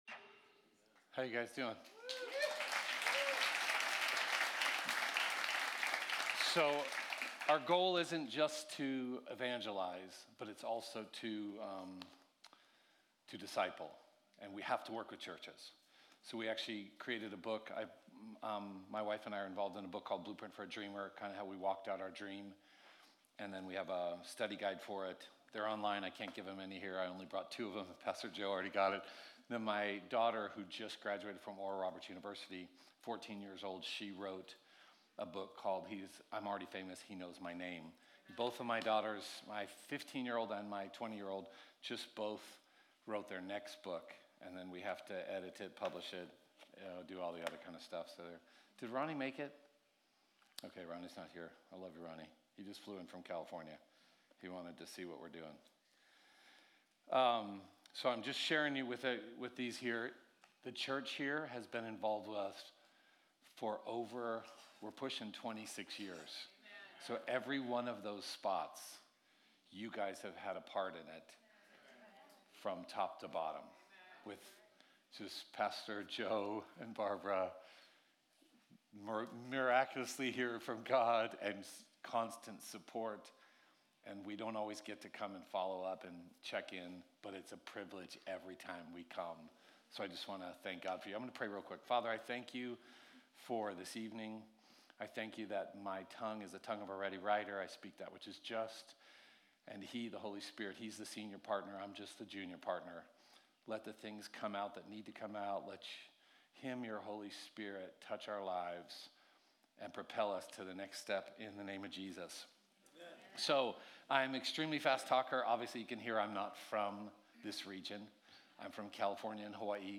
Midweek: Walking Out The Grace || Guest Speaker